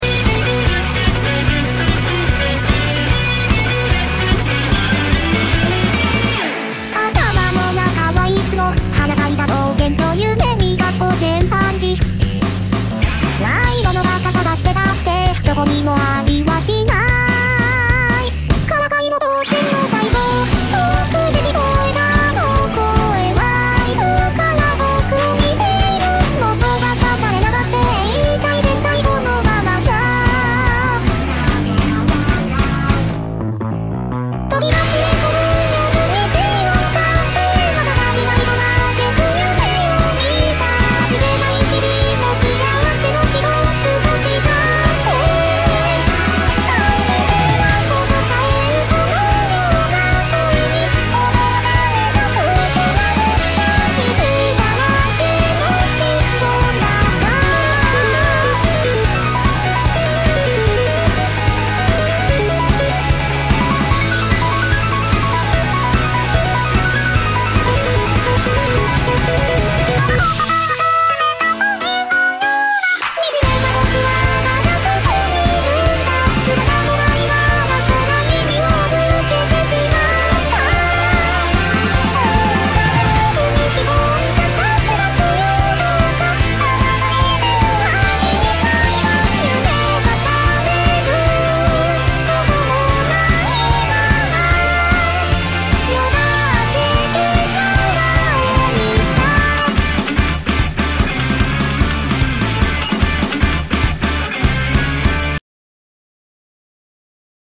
Underrated Vocaloid Song